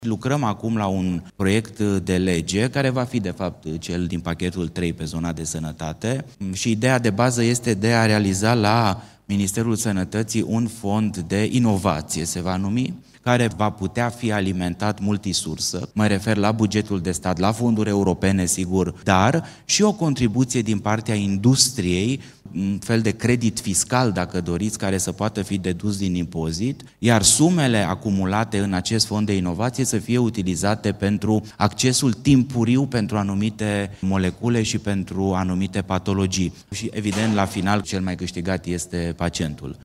Ministrul Sănătății, Alexandru Rogobete: „Ideea de bază este de a realiza la Ministerul Sănătății un fond de inovație care va putea fi alimentat multisursă”